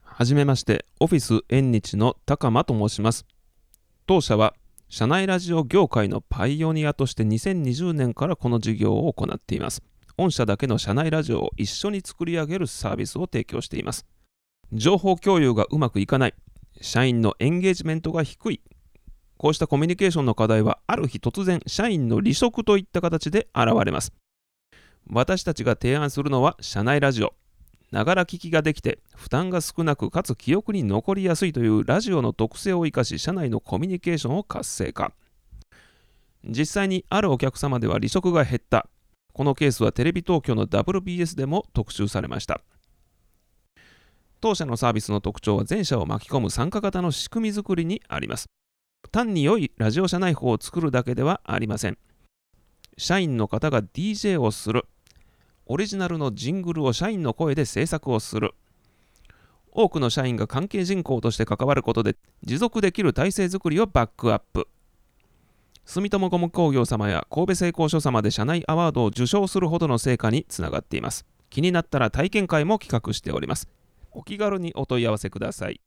この難しい課題を解決できるかもしれない施策について、話してみました。
社内ラジオ制作サービス.mp3